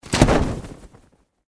target_chute.ogg